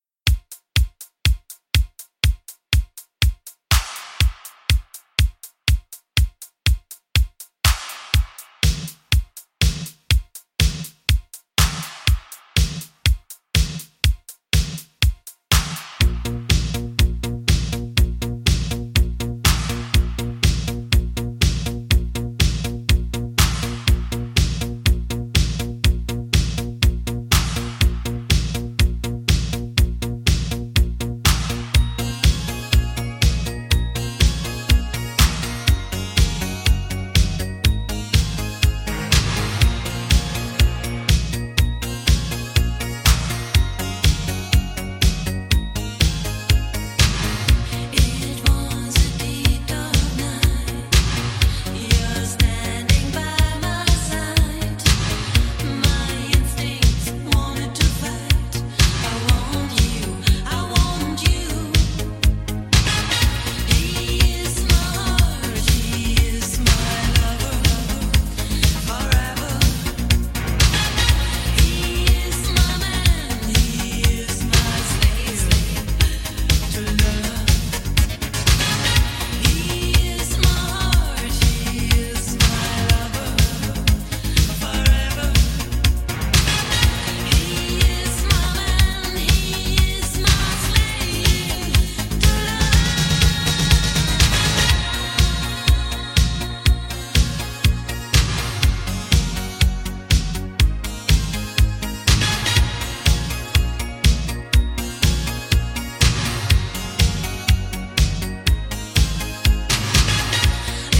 デケデケと迫るシンセベースや切なげな歌唱